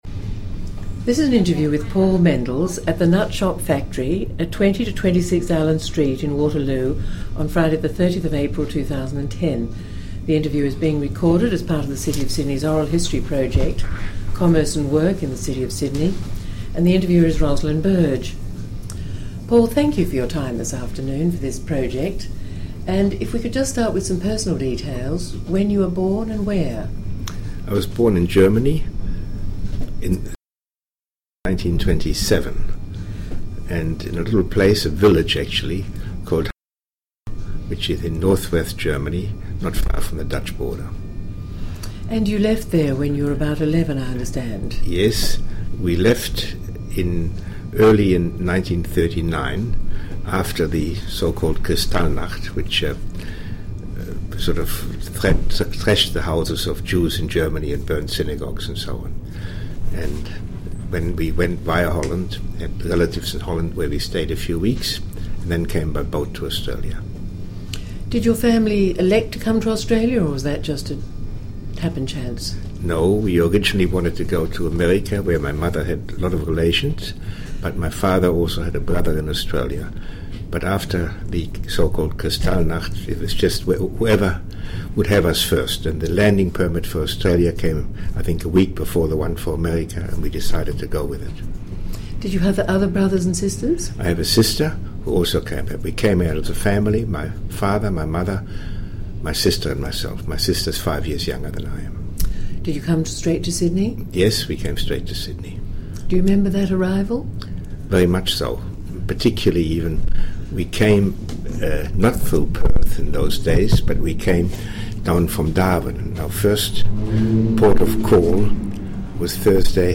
This interview is part of the City of Sydney's oral history theme: Open All Hours